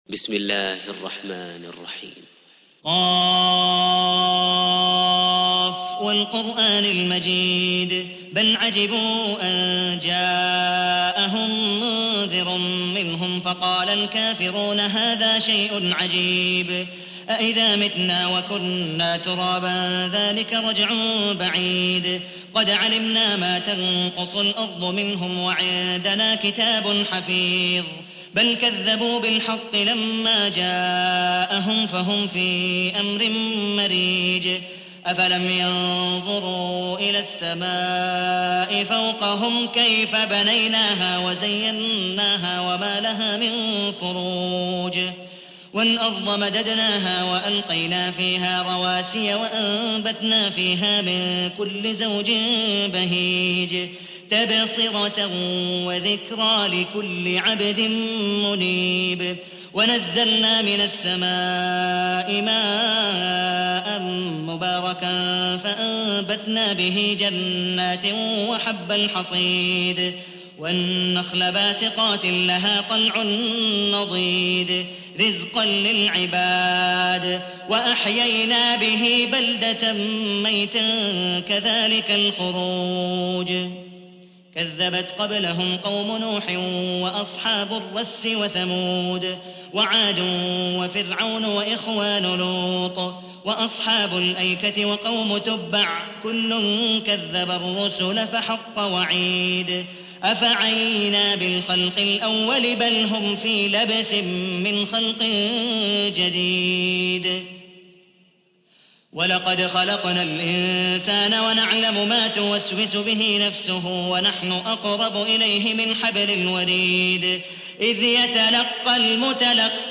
50. Surah Q�f. سورة ق Audio Quran Tarteel Recitation
Surah Sequence تتابع السورة Download Surah حمّل السورة Reciting Murattalah Audio for 50.